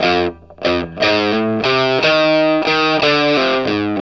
WALK1 60 F.A.wav